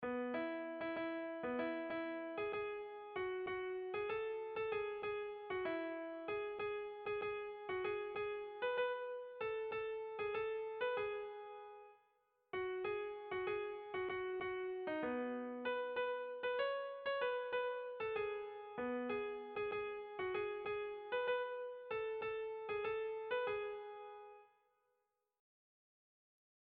Bertso melodies - View details   To know more about this section
Irrizkoa
Eibar < Debabarrena < Gipuzkoa < Basque Country
Zortziko txikia (hg) / Lau puntuko txikia (ip)
ABDB